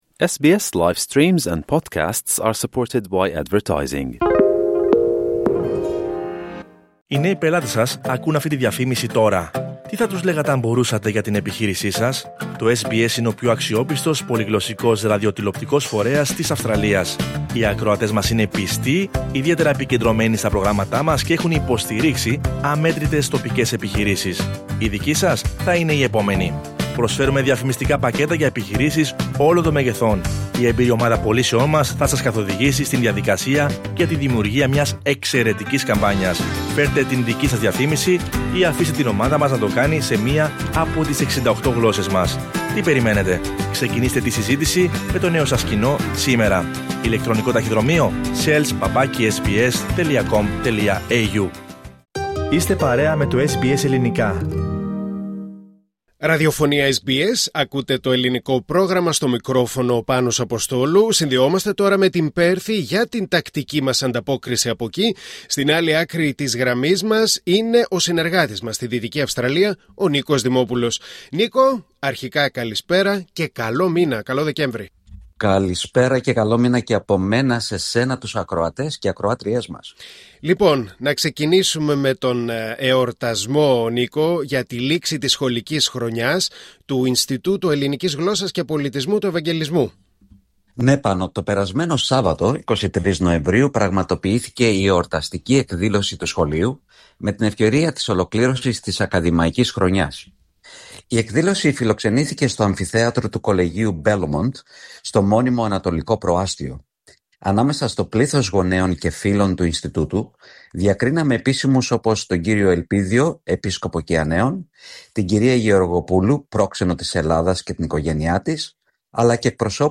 Ακούστε την εβδομαδιαία ανταπόκριση από την Δυτική Αυστραλία